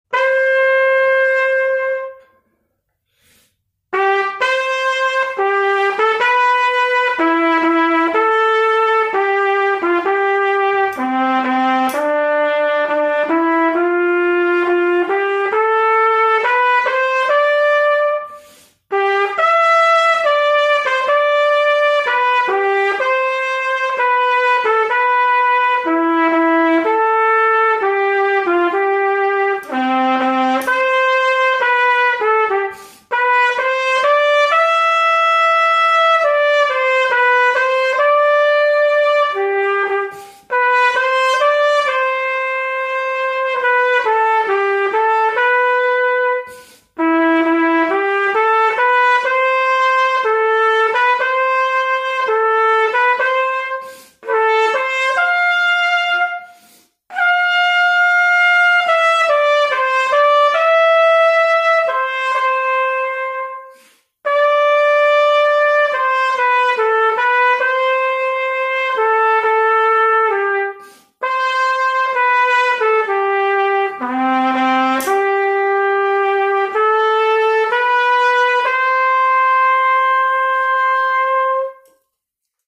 на трубе соло